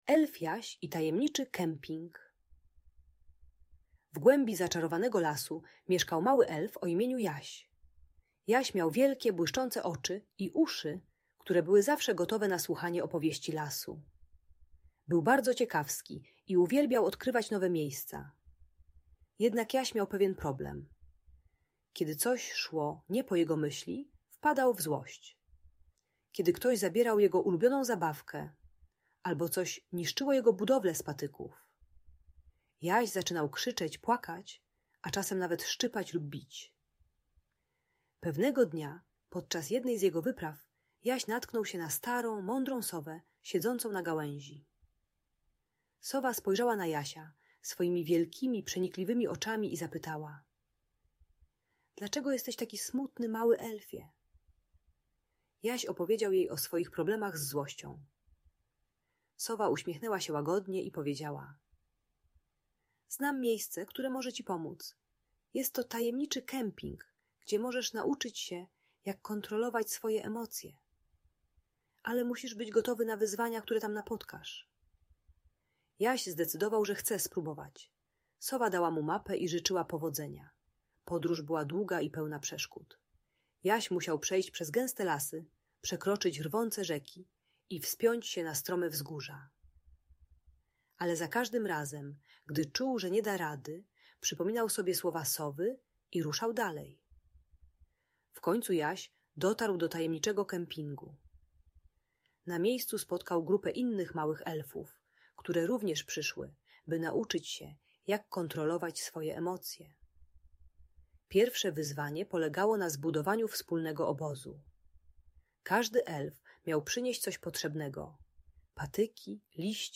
Elf Jaś i Tajemniczy Kemping - Bunt i wybuchy złości | Audiobajka